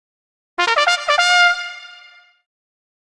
game_success.mp3